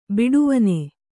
♪ biḍuvane